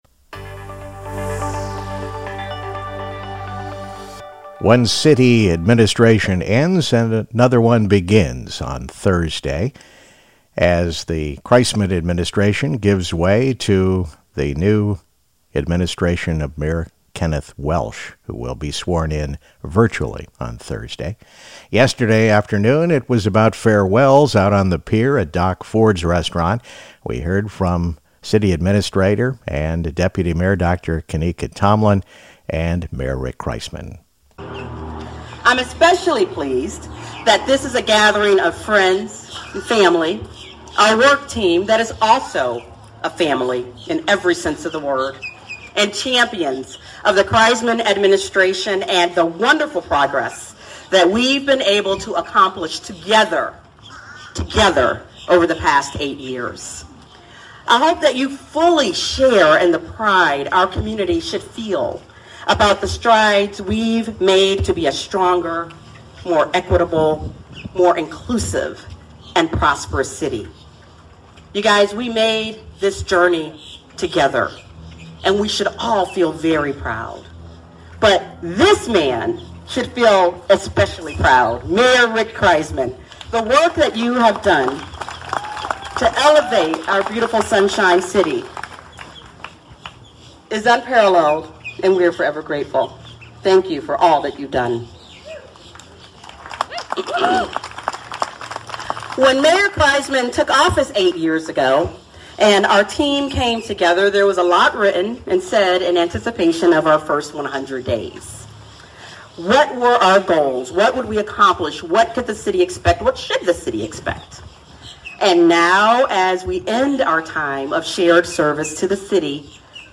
"Faring Well" Farewell Address. St. Pete Mayor Rick Kriseman & Deputy Mayor Tomalin 1-3-22